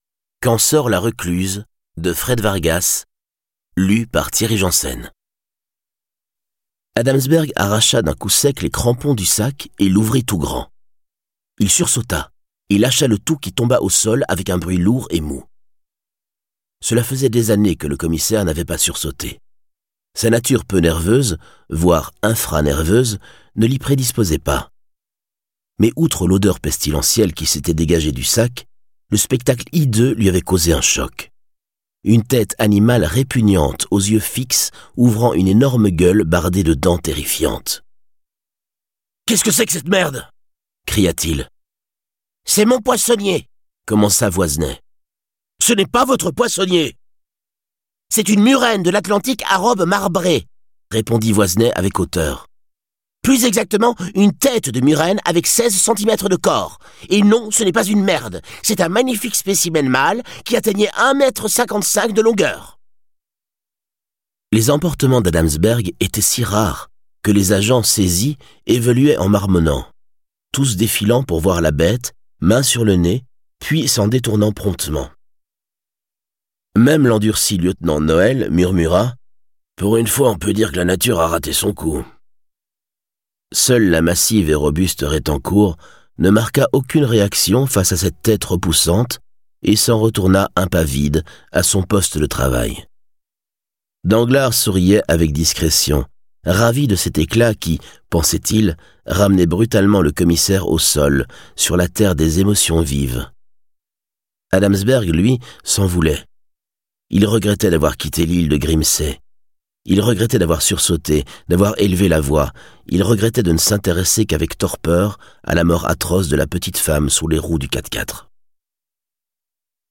Prix Audiolib/Roman policier / Thriller